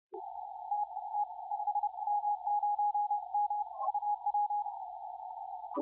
5W0M 15CW